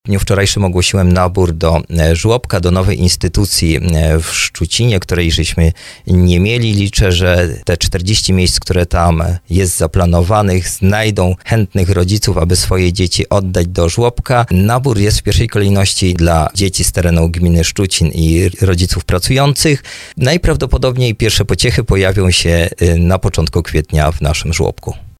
Do żłobka będzie mogło uczęszczać 40 dzieci, które skończyły 20. tydzień życia. Mówił o tym w audycji Słowo za Słowo burmistrz Tomasz Bełzowski.